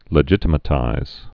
(lə-jĭtə-mə-tīz)